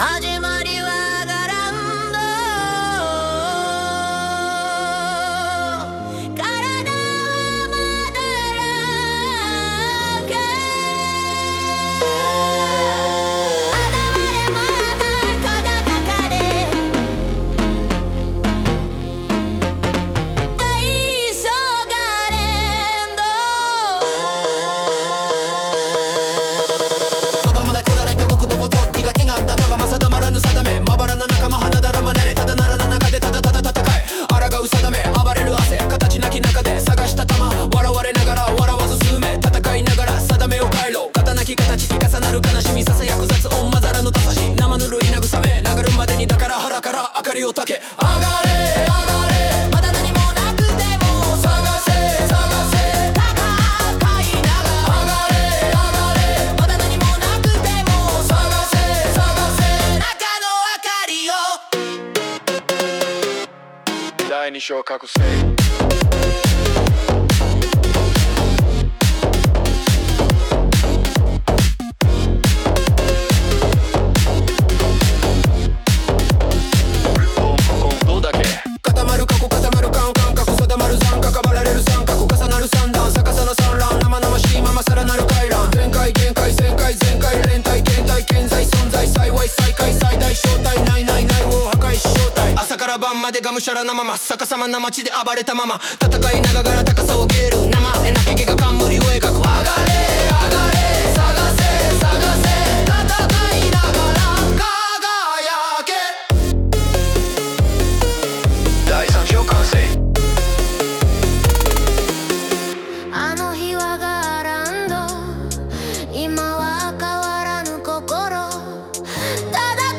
イメージ：EDM,テクノ,エレクトロ,男性ボーカル,ラップ,パンク